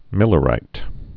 (mĭlə-rīt)